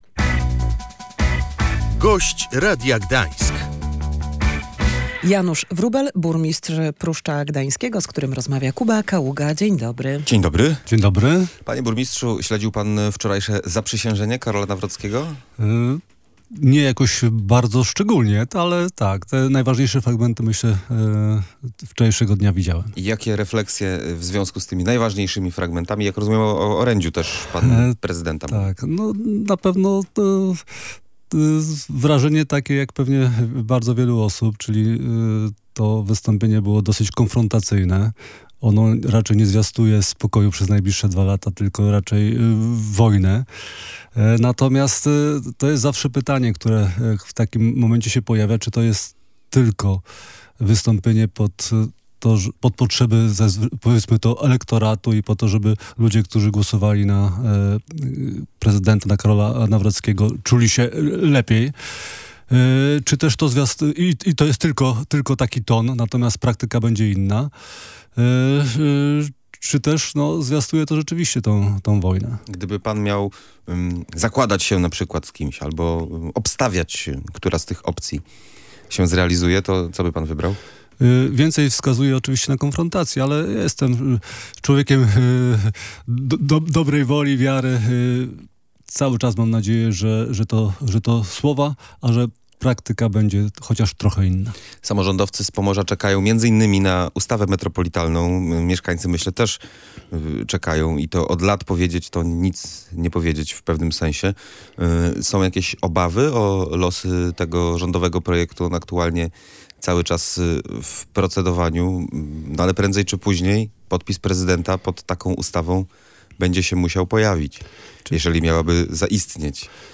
Wielomilionowe inwestycje pozwoliły ochronić Pruszcz Gdański przez zalaniami po ostatnich ulewach – wskazywał na naszej antenie burmistrz Janusz Wróbel, który był Gościem Radia Gdańsk.